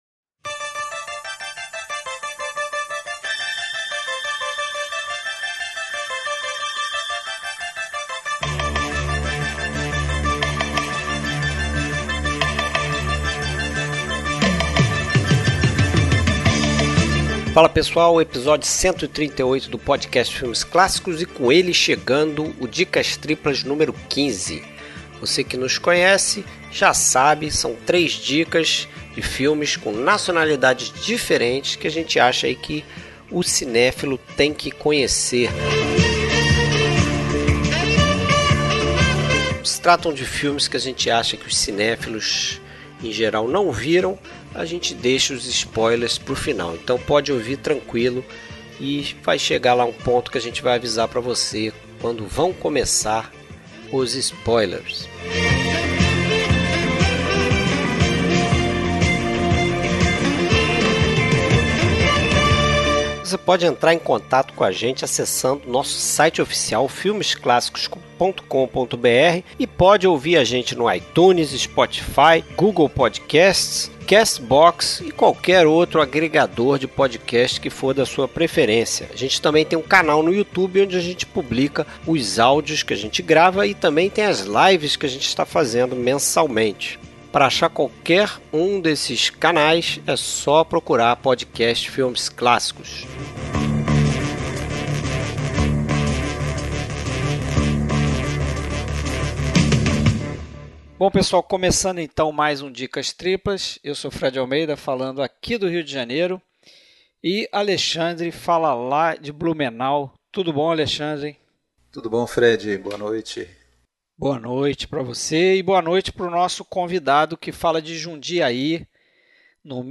Trilha Sonora: Trilhas sonoras dos filmes comentados neste episódio.